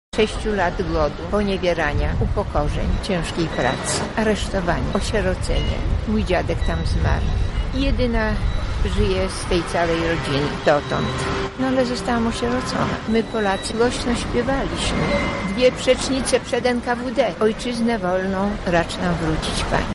W Lublinie odbyły się obchody poświęcone 80. rocznicy agresji sowieckiej na Polskę